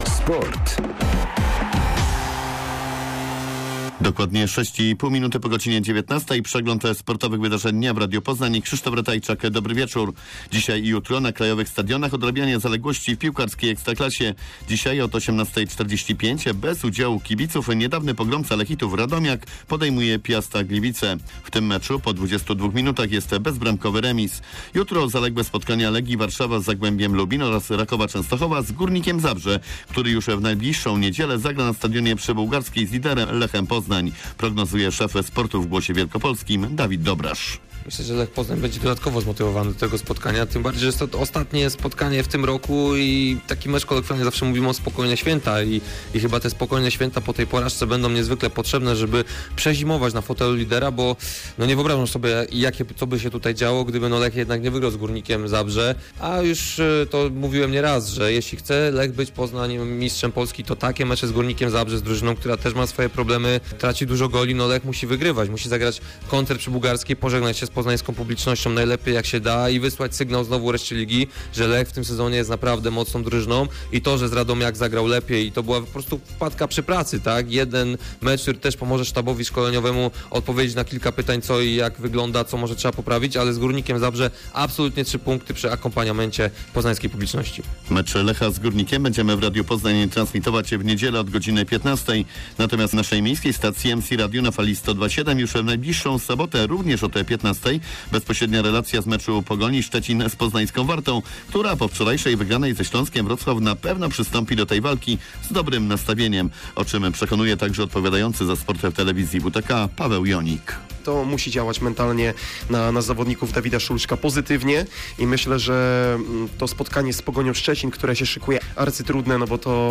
14.12.2021 SERWIS SPORTOWY GODZ.19:05